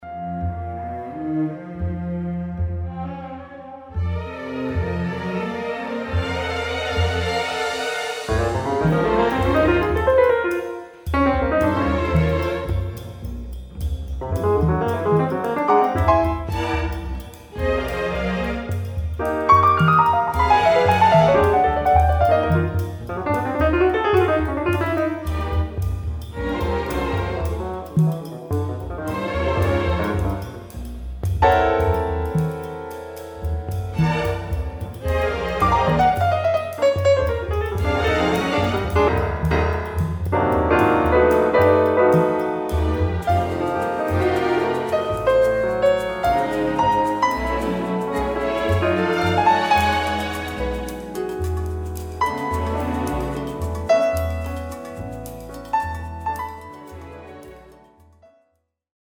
Piano with strings